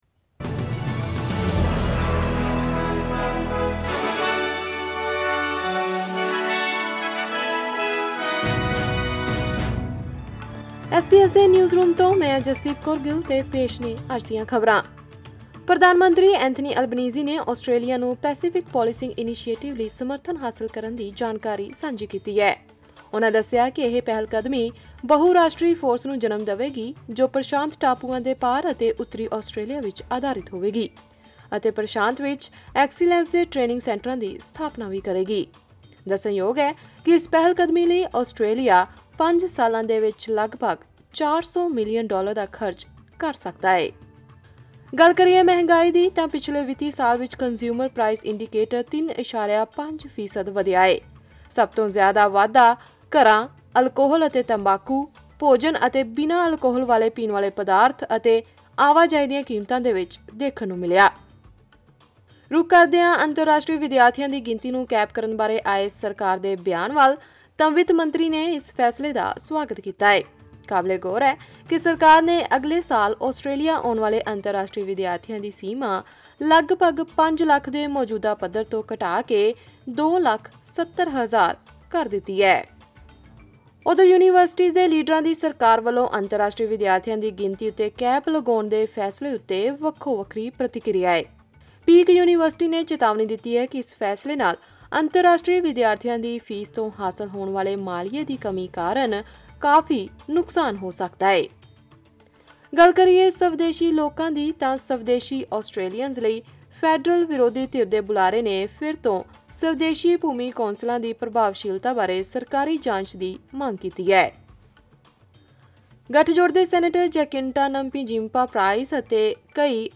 ਐਸ ਬੀ ਐਸ ਪੰਜਾਬੀ ਤੋਂ ਆਸਟ੍ਰੇਲੀਆ ਦੀਆਂ ਮੁੱਖ ਖ਼ਬਰਾਂ: 28 ਅਗਸਤ 2024